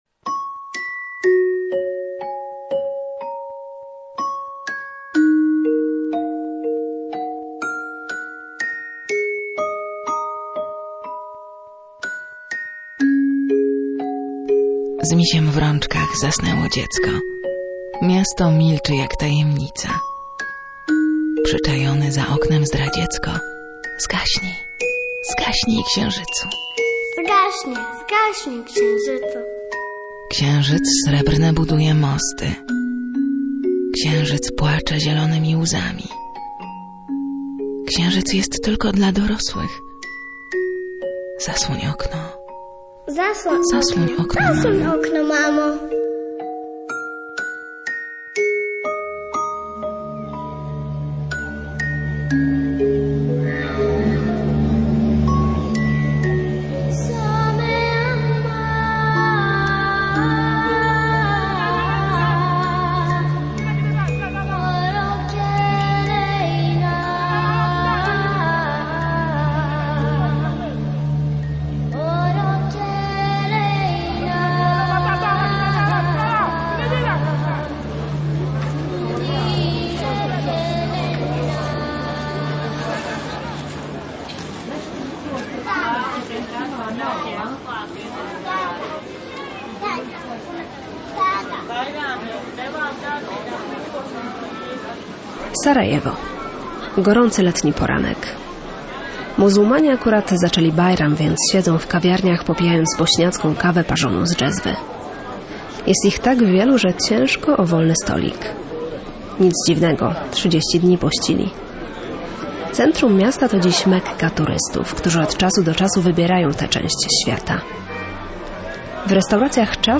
O dzieciństwie w trudnym czasie wojny. Reportaż „Niebo nad Sarajewem”